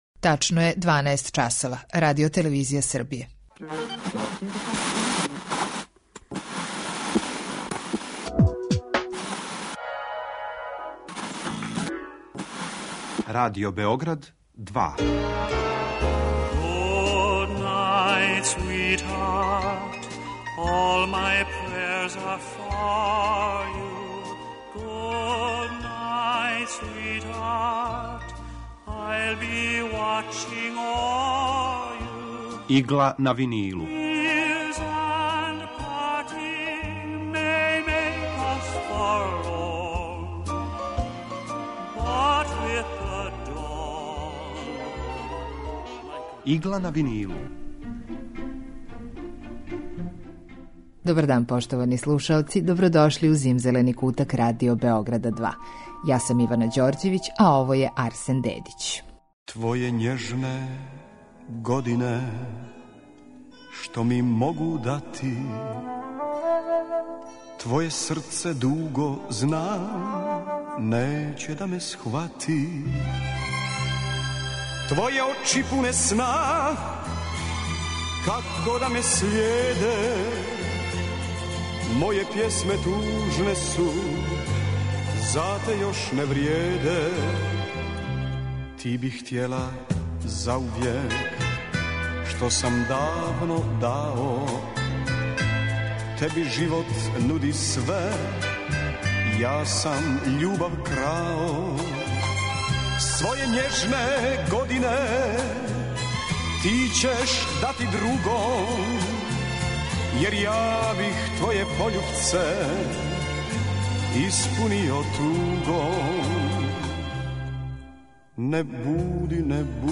Емисија евергрин музике
Котлићеве композиције слушаћете у извођењу Лоле Новаковић, Наде Кнежевић и Оливере Марковић.